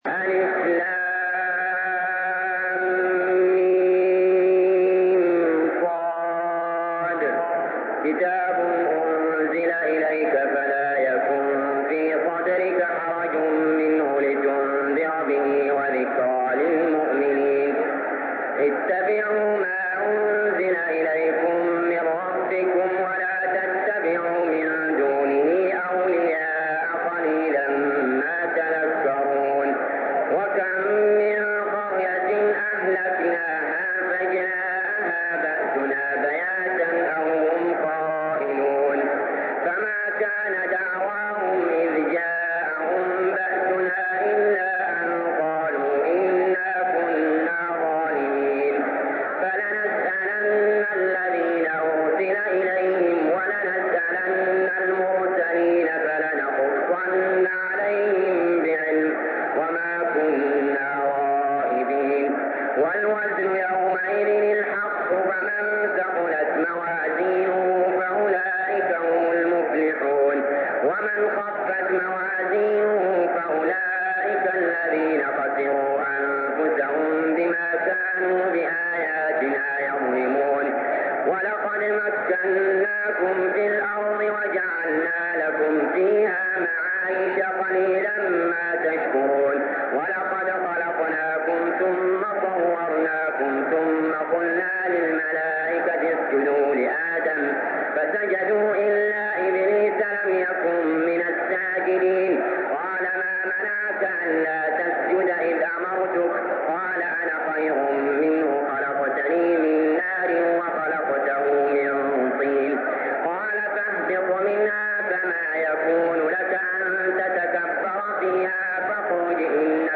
المكان: المسجد الحرام الشيخ: علي جابر رحمه الله علي جابر رحمه الله الأعراف